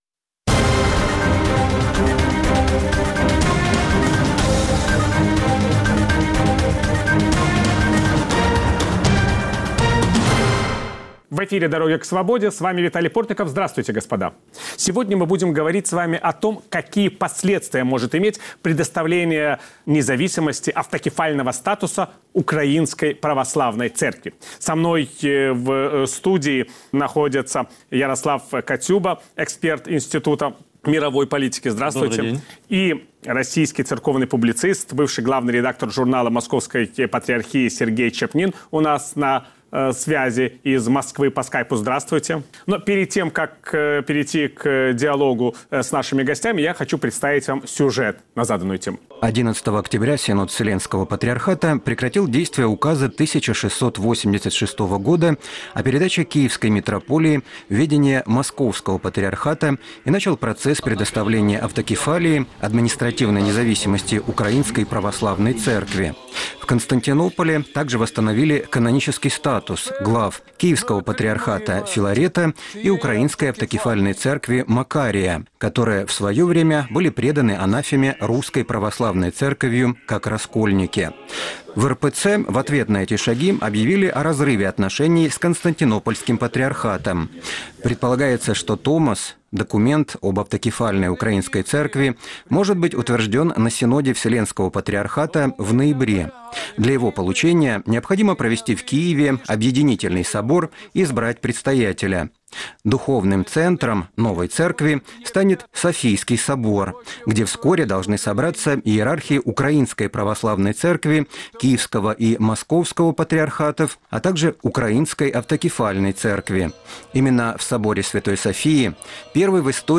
Диалог накануне Томоса. Почему автокефалия украинской церкви стала новым вызовом для отношений России и Украины?